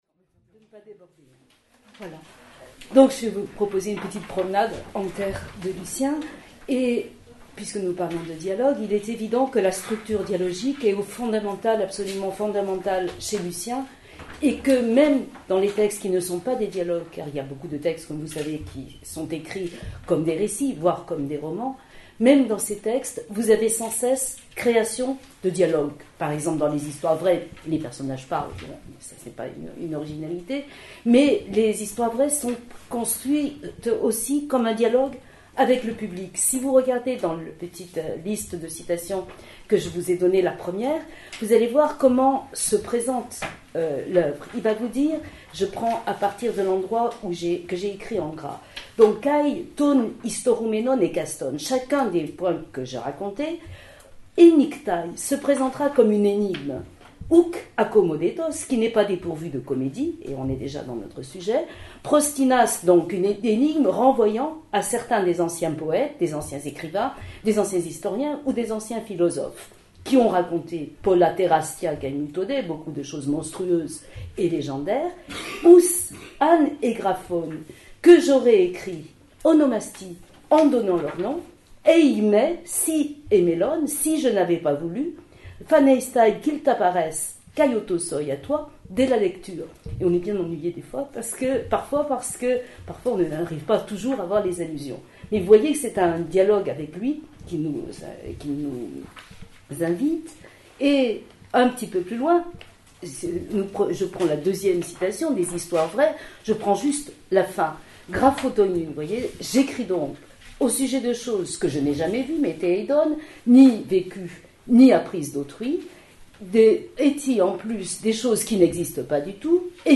Cette manifestation se déroulera dans les locaux du Collège Sévigné : 28, rue Pierre Nicole, devant quelques auditeurs.